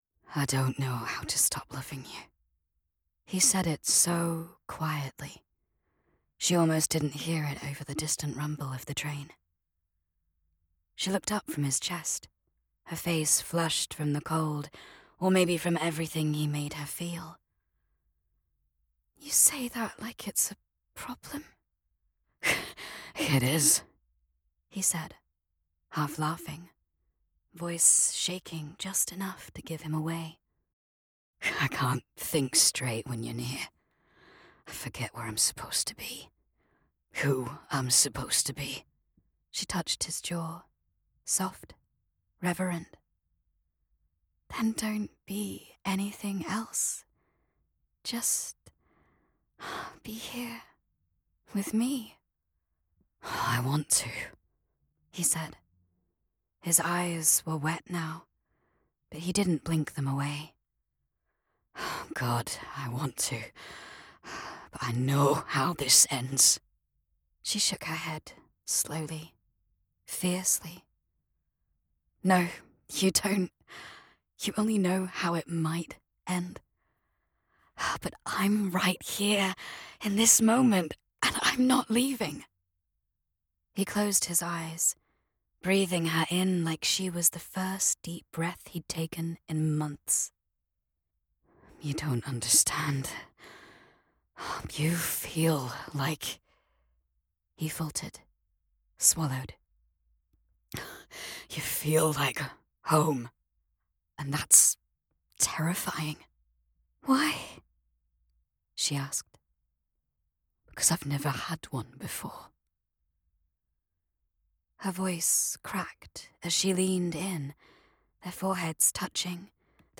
British Female Audiobook Narrator
3rd person, F/M, RP, Northern. Intimate, heartfelt.
romance.mp3